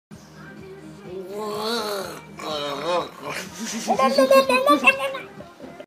Play, download and share Naaa Ulululu original sound button!!!!
a-little-brain-fart.mp3